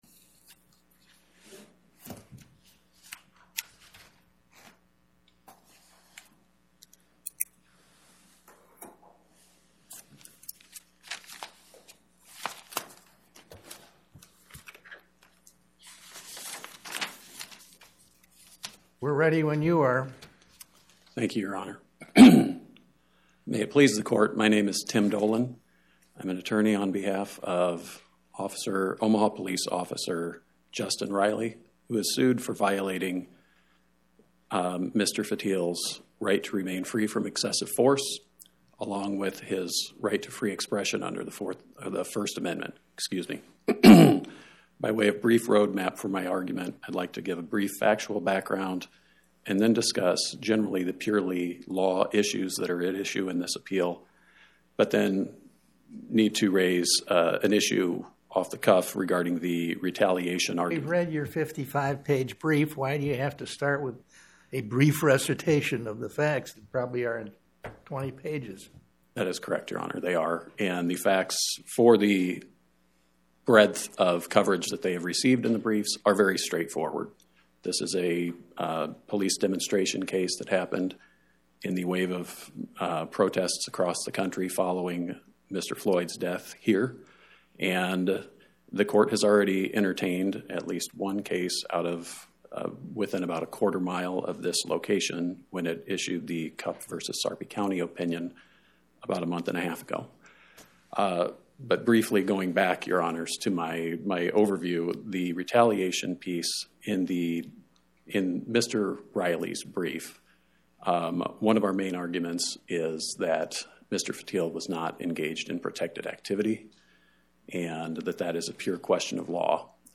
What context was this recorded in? Oral argument argued before the Eighth Circuit U.S. Court of Appeals on or about 02/10/2026